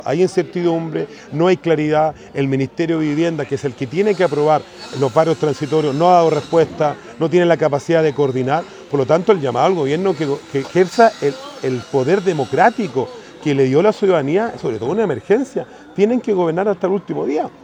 Consultado el alcalde de Penco, indicó que el sector Geo Chile es uno de los que tendría que ser reubicado en “Aldeas de Emergencia”, esto a la espera de un proyecto definitivo.